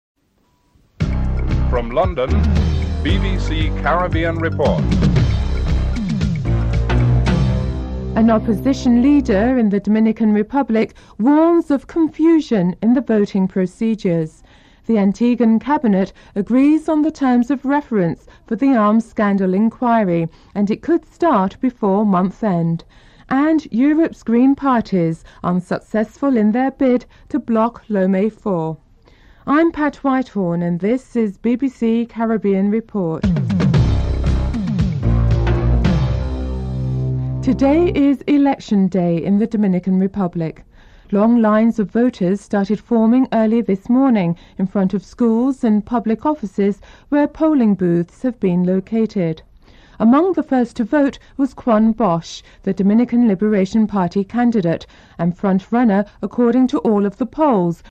1. Headlines (00:00-00:36)
3. Antiguan Cabinet agrees on the terms of reference for the arms scandal enquiry. Comments from Tim Hector, leader of the Antigua Caribbean Liberation Movement (02:28-04:33)